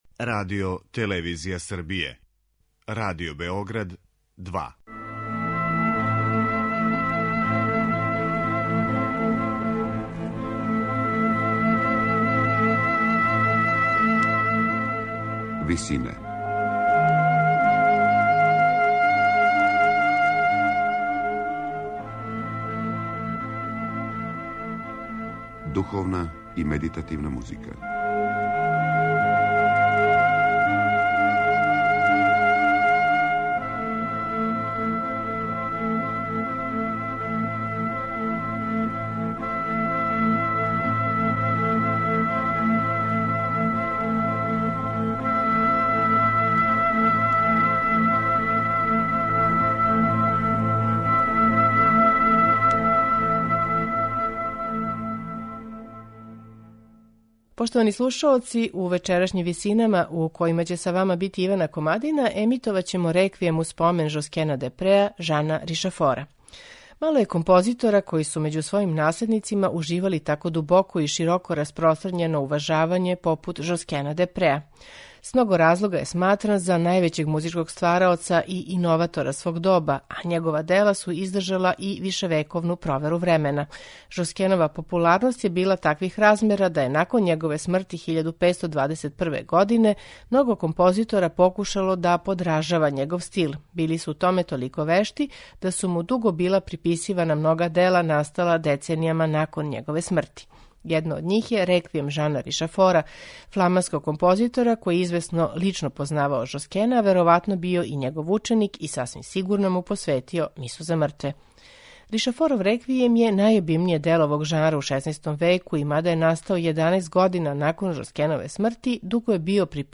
Ришафоров 'Реквијем у спомен Жоскена де Преа' изводи Huelgas-Ensemble, под управом Паула фан Нејвла.
медитативне и духовне композиције